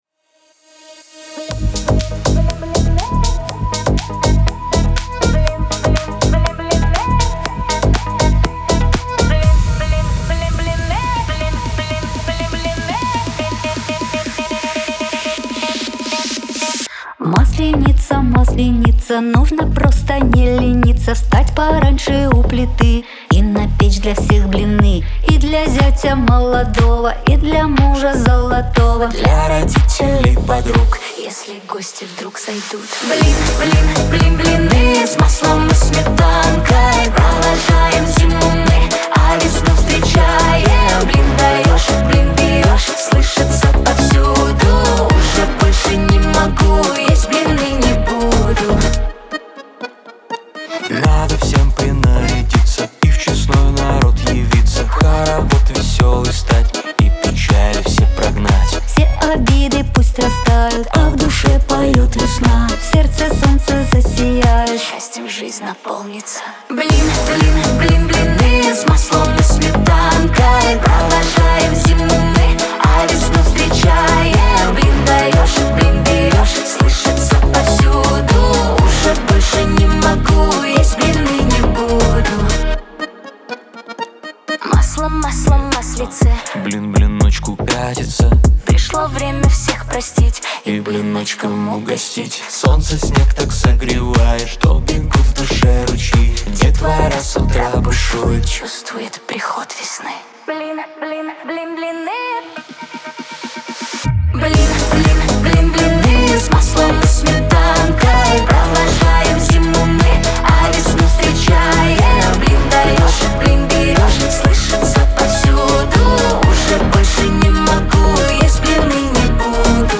эстрада
дуэт , pop
диско